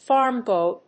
/ˈfɑˌrmbɔɪ(米国英語), ˈfɑ:ˌrmbɔɪ(英国英語)/